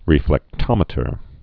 (rēflĕk-tŏmĭ-tər)